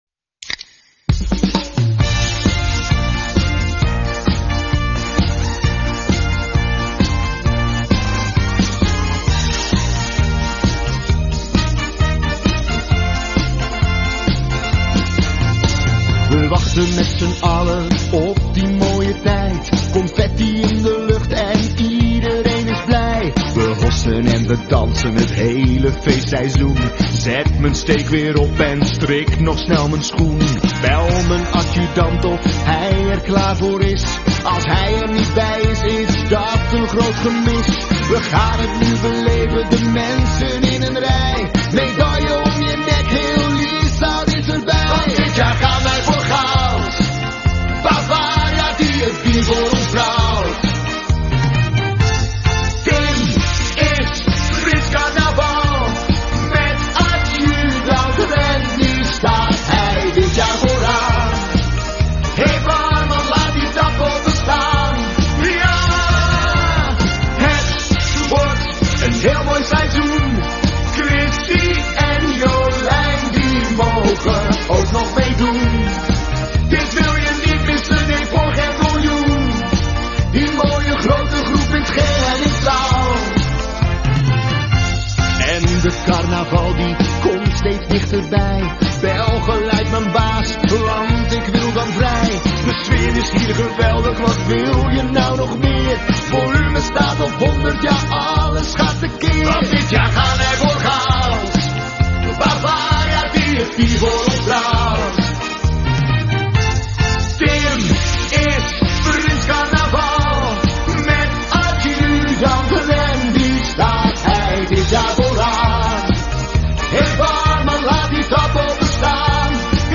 Ook hebben ze een eigen carnavalsnummer gemaakt die we de komende tijd veel zullen horen.